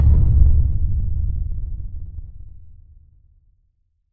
Low End 19.wav